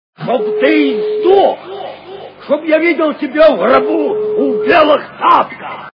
» Звуки » Из фильмов и телепередач » Бриллинтова рука - Шоб ты издох Шоб я видел тебя у гробу у белых тапках
При прослушивании Бриллинтова рука - Шоб ты издох Шоб я видел тебя у гробу у белых тапках качество понижено и присутствуют гудки.